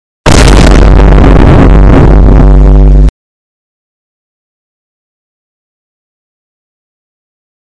Vine Boom 333 Sound Button - Free Download & Play